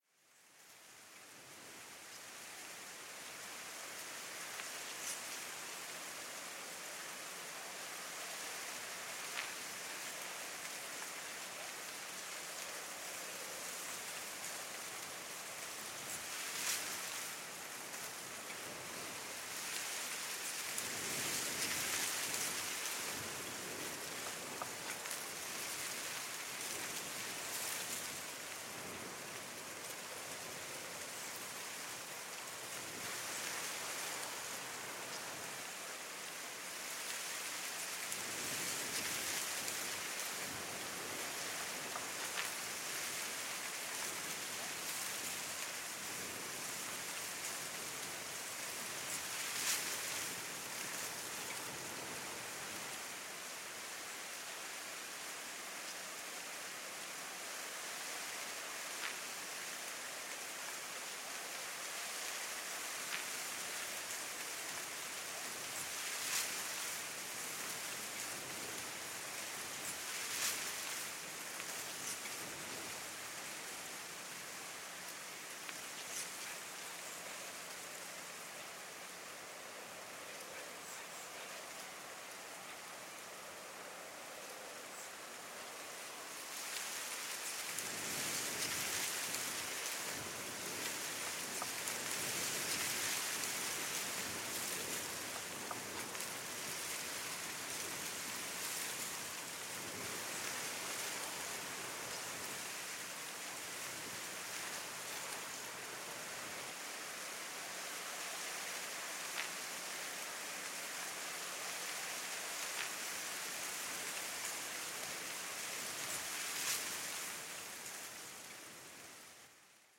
Звуки сухих листьев
Звук легкого ветра, колышущего листья на деревьях